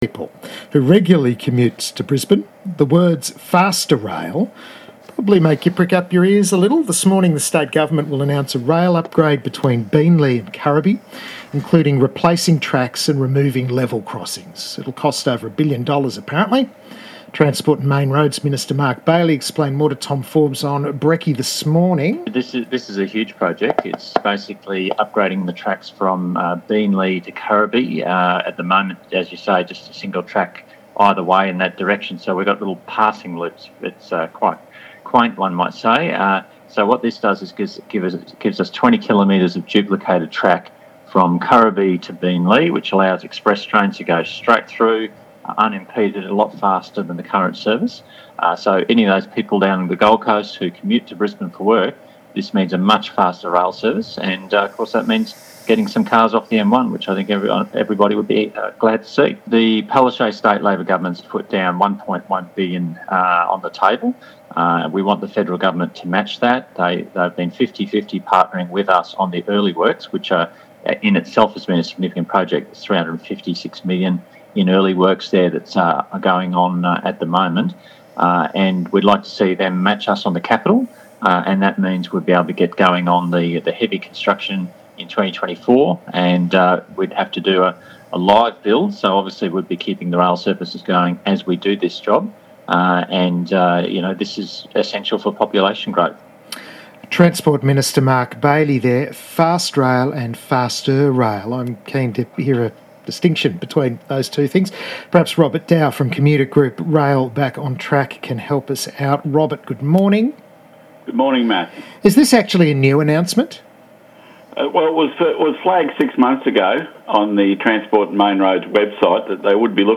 Been invited to an interview on ABC Gold Coast at 8.30am.